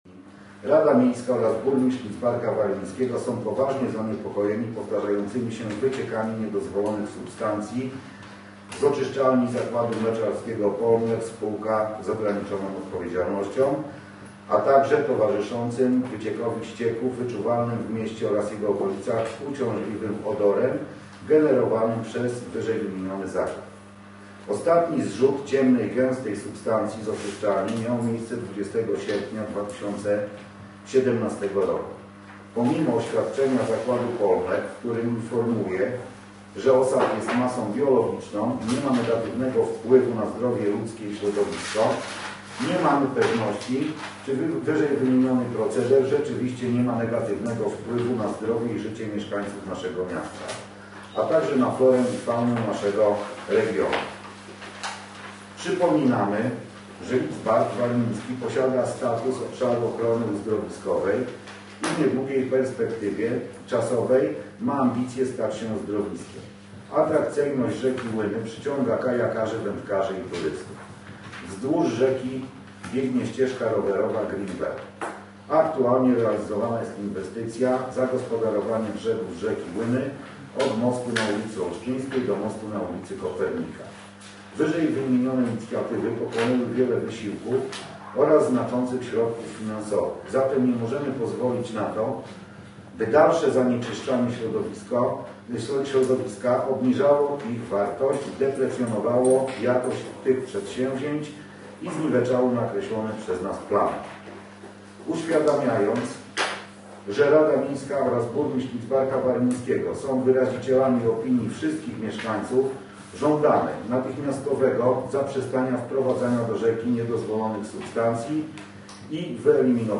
Również dzisiaj miała miejsce nadzwyczajna sesja Rady Miasta, podczas której Burmistrz i Rada Miasta wyraziły opinię na temat zanieczyszczenia rzeki i powietrza przez wspomniany zakład mleczarski.